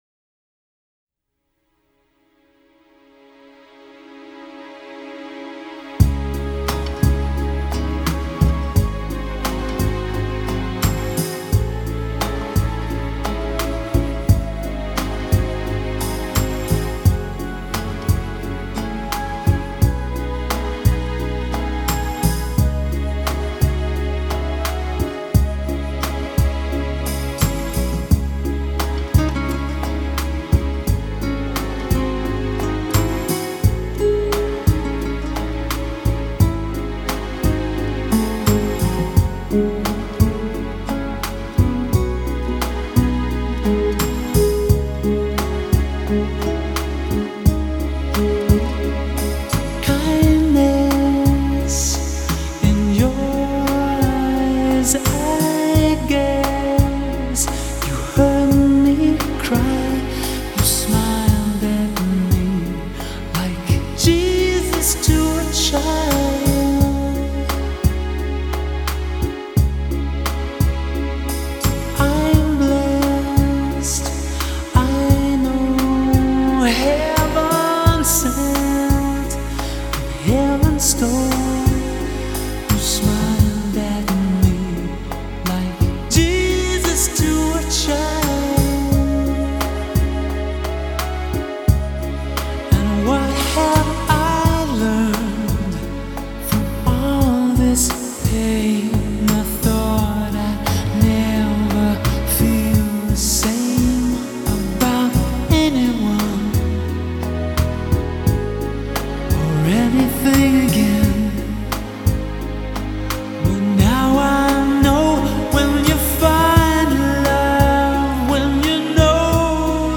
блюз
поп-музыка